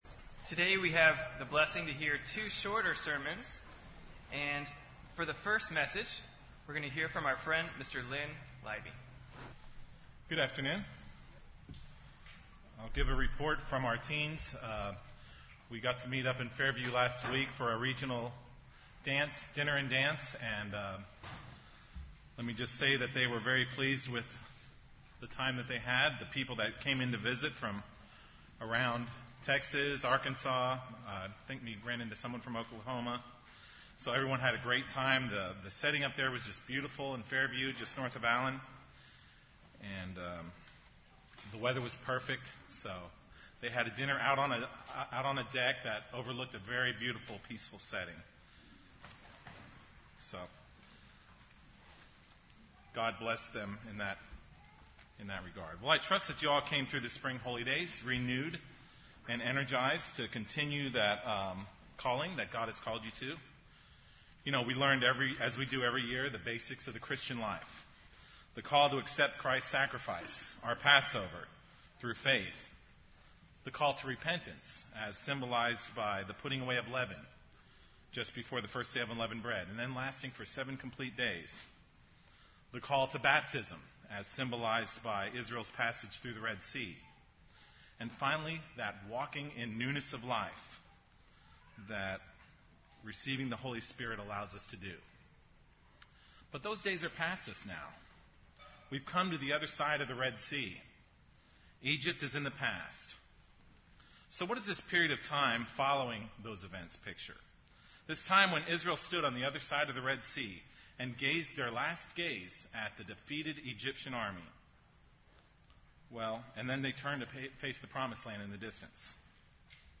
This sermon will explore what is involved with that walk of newness of life.